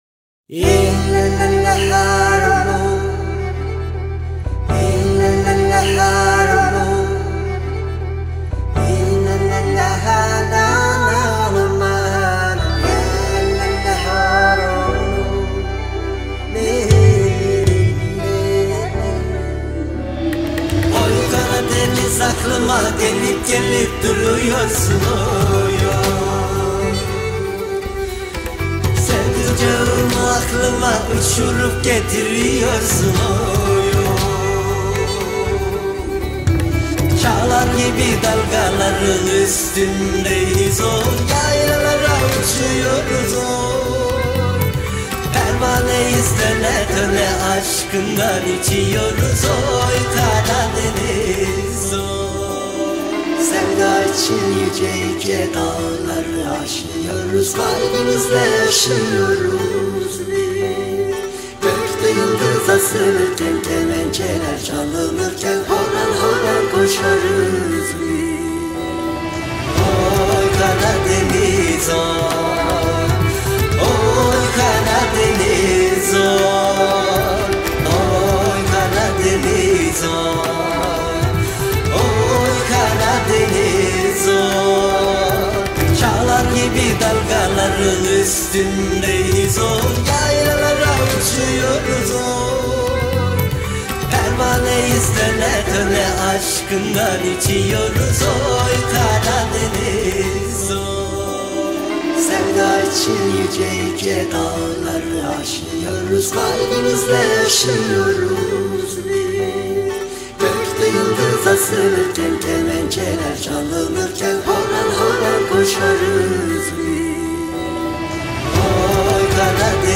duygusal huzurlu rahatlatıcı fon müziği.